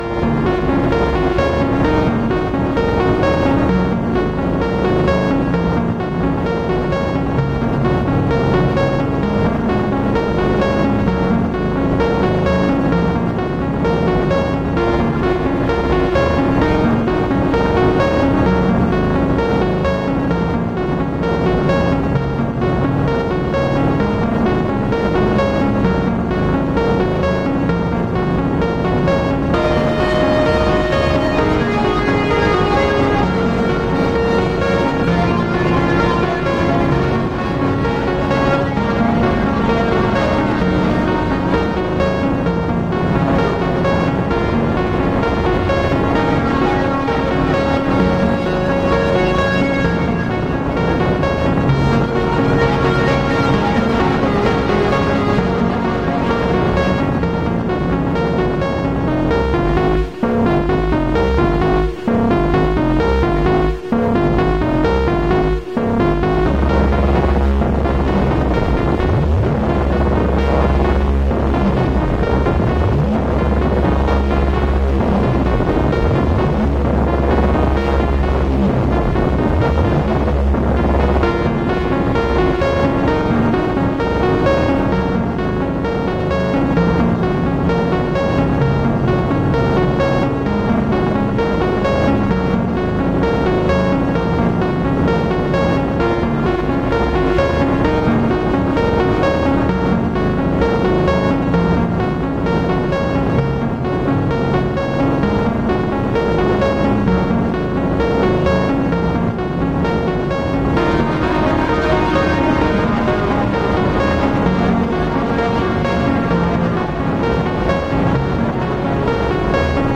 vaporwave, chiptune, videogame music, vgm, midiwave,